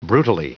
Prononciation du mot brutally en anglais (fichier audio)
Prononciation du mot : brutally